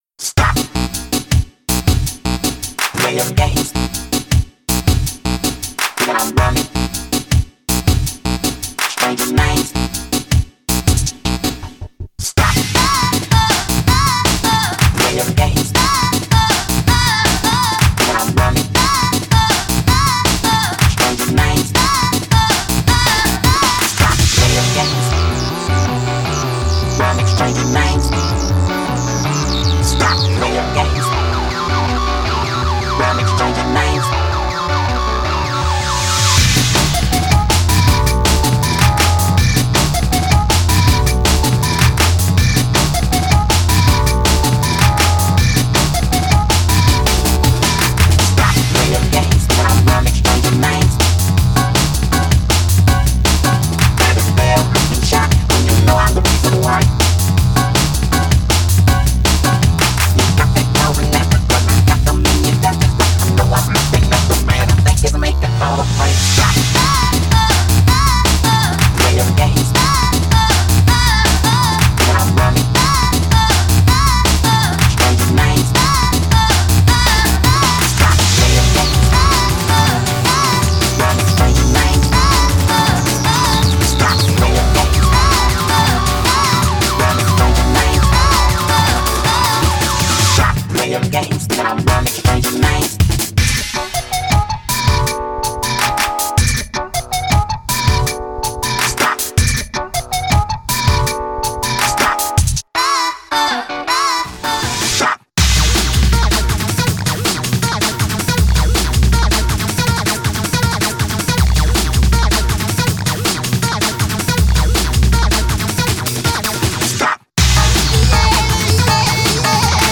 BPM80-160
Audio QualityPerfect (High Quality)
It's an energetic song sure to get you pumped.